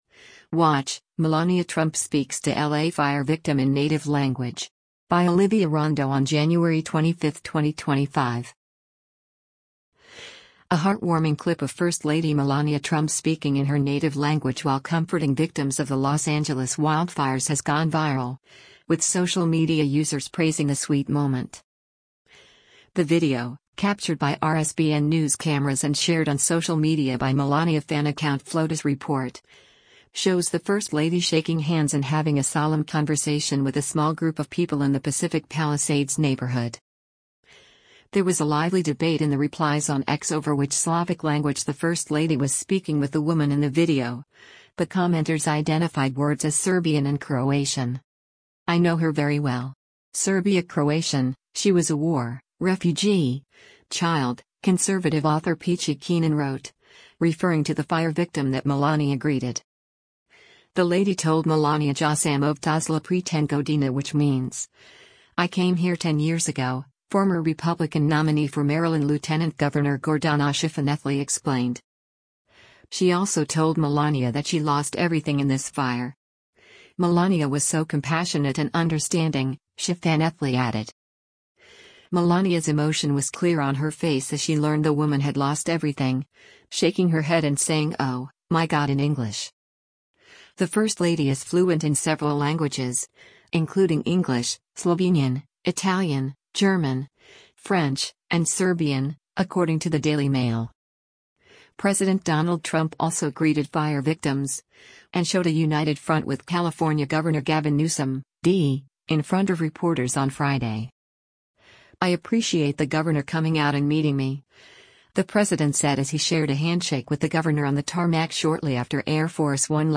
The video, captured by RSBN news cameras and shared on social media by Melania fan account “FLOTUS Report,” shows the first lady shaking hands and having a solemn conversation with a small group of people in the Pacific Palisades neighborhood:
Melania’s emotion was clear on her face as she learned the woman had lost everything, shaking her head and saying “Oh, my God” in English.